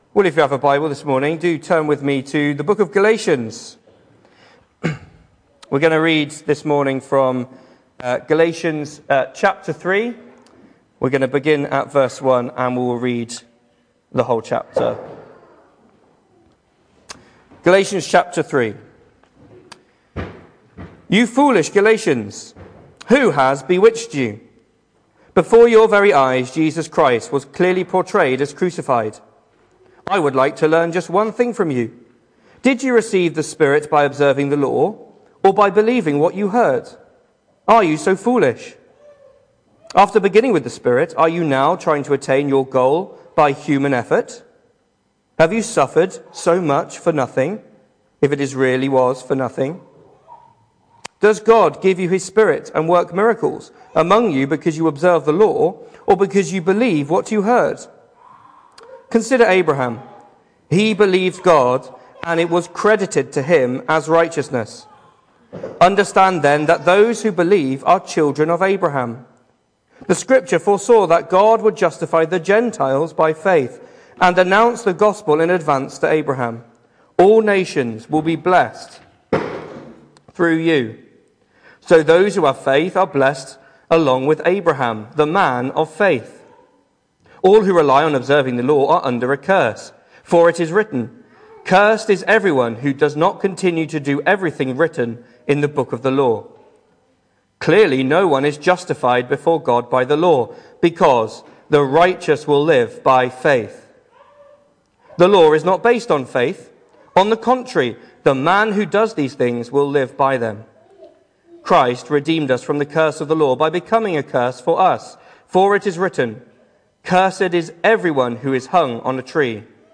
Hello and welcome to Bethel Evangelical Church in Gorseinon and thank you for checking out this weeks sermon recordings.
The 26th of October saw us host our Sunday morning service from the church building, with a livestream available via Facebook.